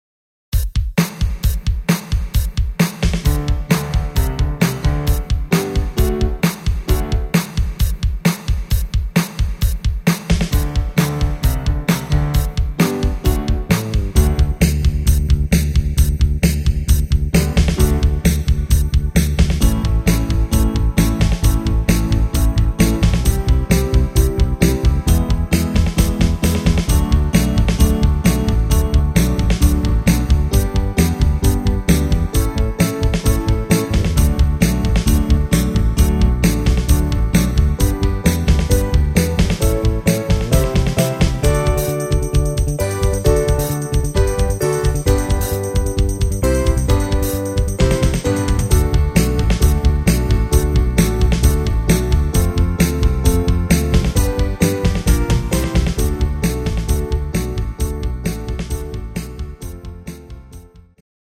instr. Mundharmonika